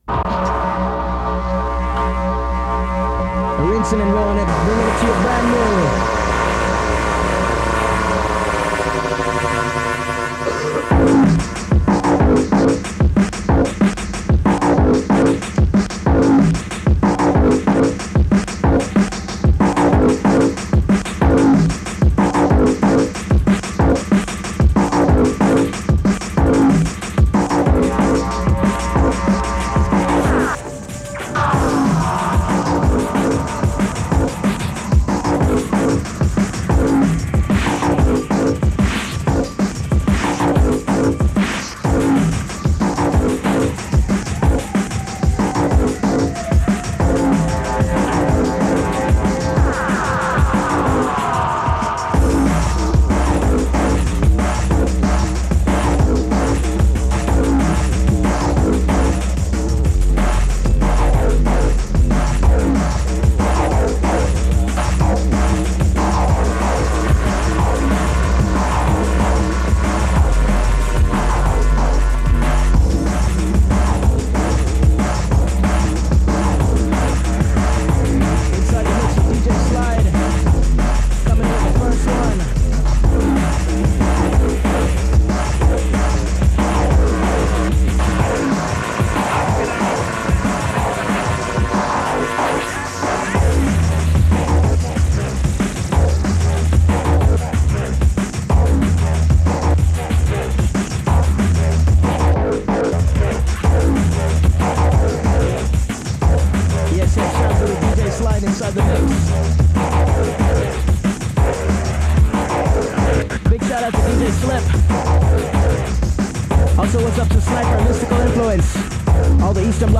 Specifically on side B there are some cool weird tracks.